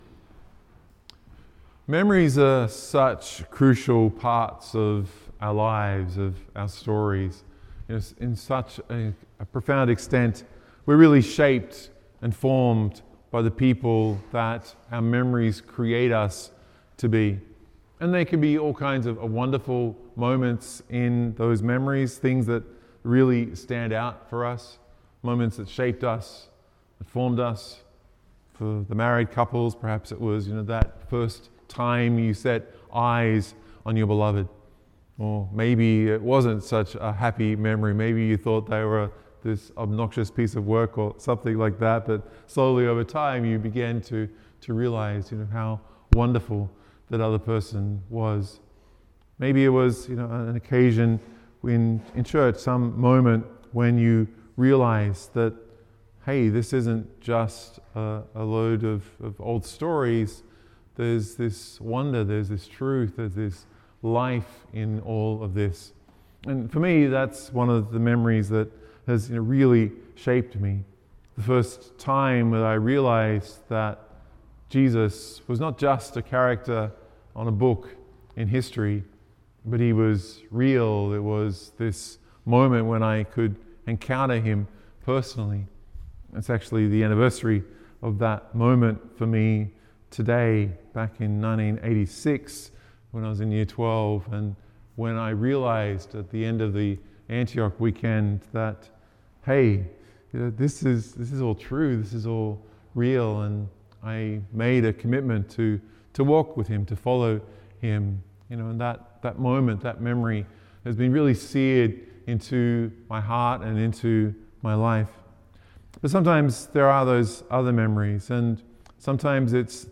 Download or Play MP3 MP3 media (5pm Vigil)